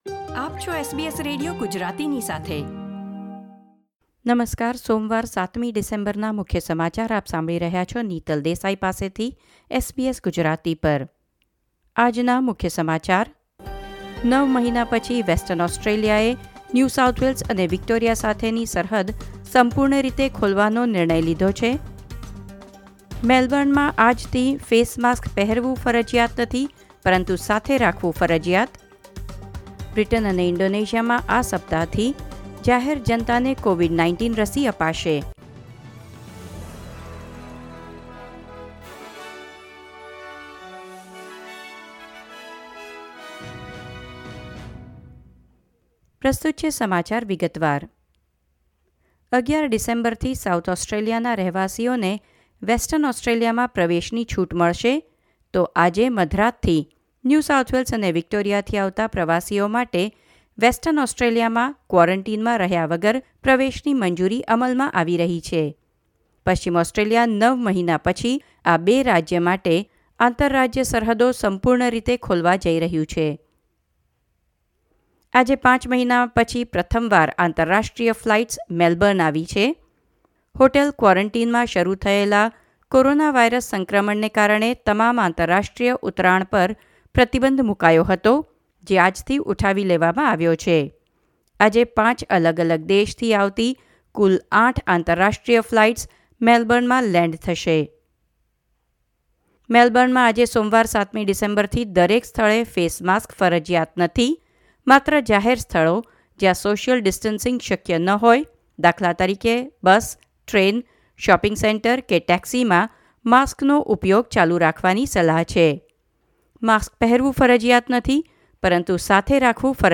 gujarati_0712_newsbulletin.mp3